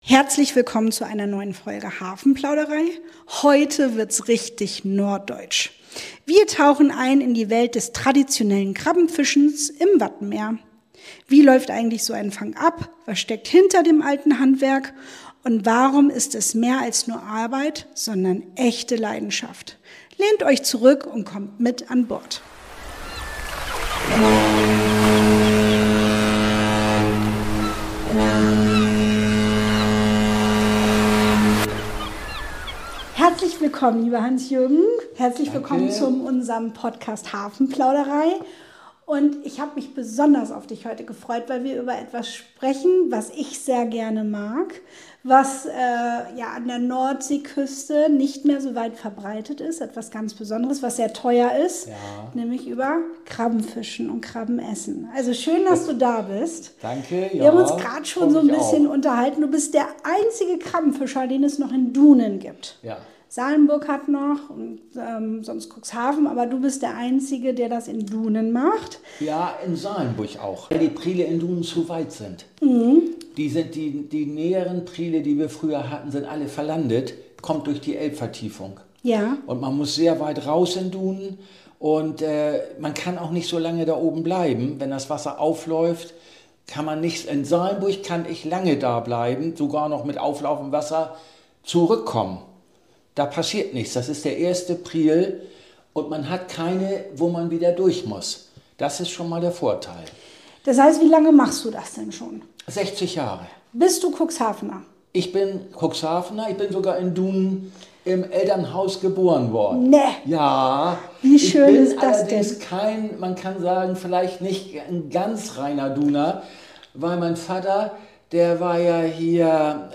In dieser Folge geht’s mit Gummistiefeln ins Watt!